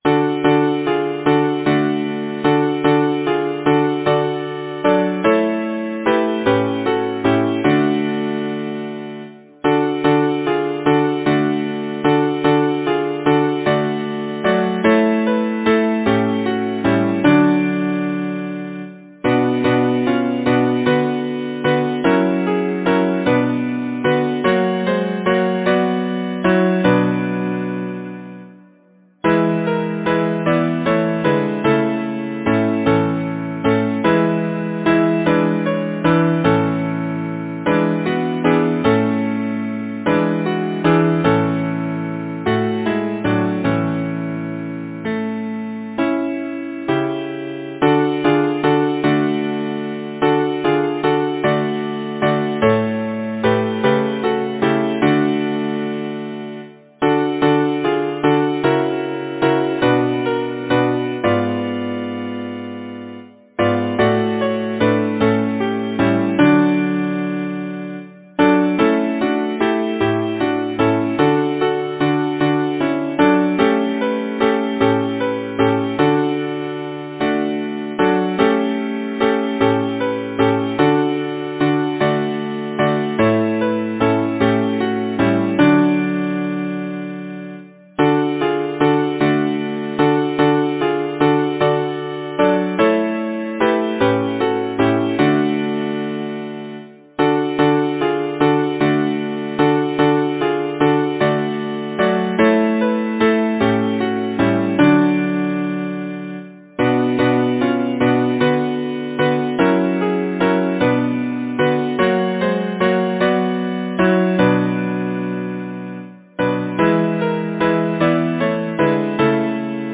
Title: Merrily row Composer: John Rogers Thomas Lyricist: Number of voices: 4vv Voicing: SATB Genre: Secular, Partsong
Language: English Instruments: A cappella
First published: ca. 1880 Copp, Clark & Co. Description: BARCAROLE